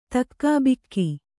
♪ takkābikki